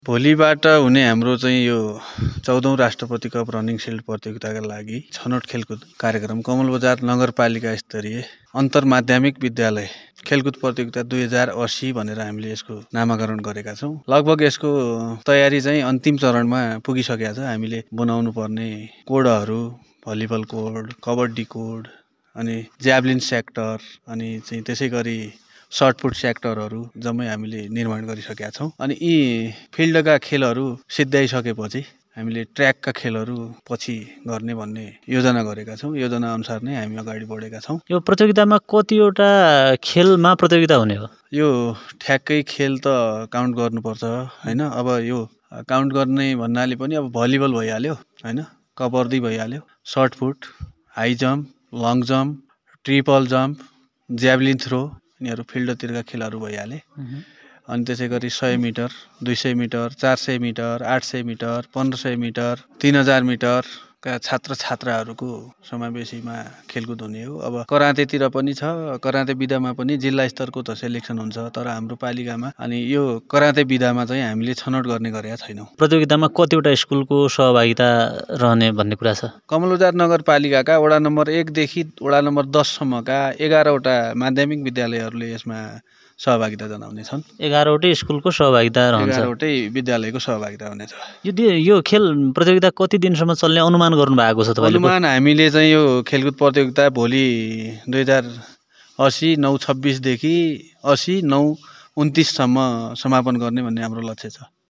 कुराकानिको शुरुमा उनलाई हामीले भोलीदेखी शुरु हुने १४ औँ राष्ट्रपति रनिङ शिल्ड प्रतियोगीताको तयारी केकस्तो छ भनेर सोधेका छौँ ।